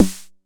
Snare 909 Tune13 f.wav